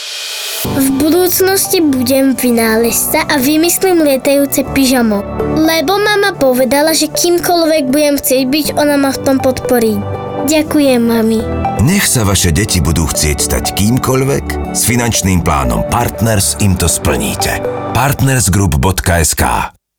Rozhlasový spot chlapec